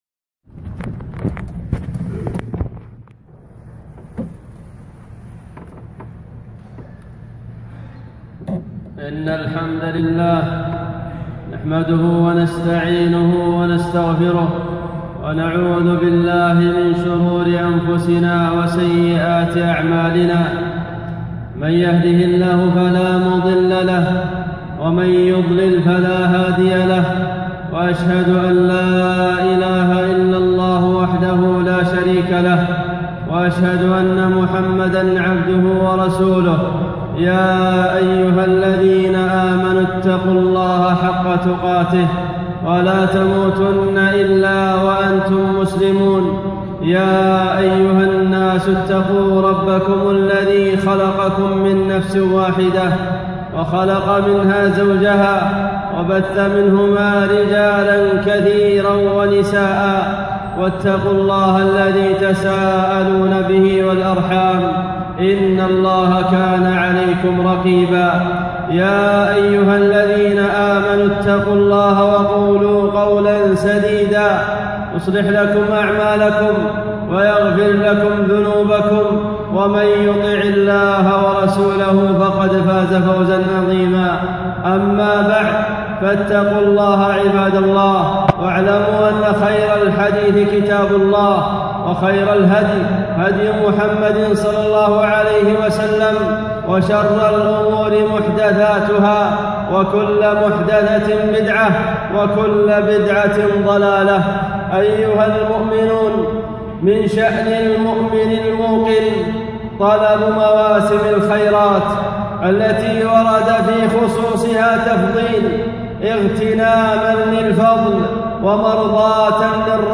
خطبة - عشر ذي الحجة